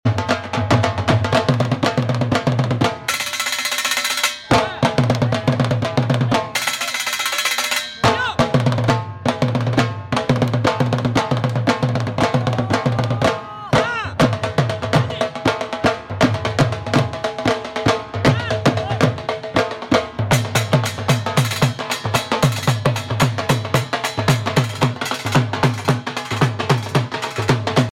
Dhol beat